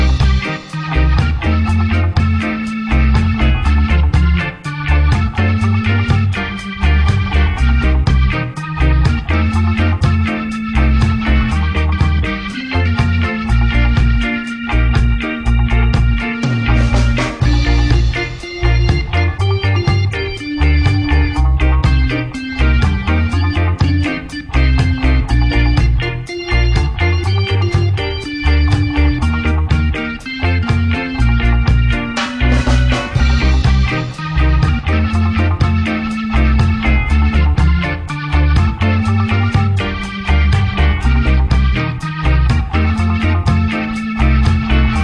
リラックスムードのロックステディでカヴァー！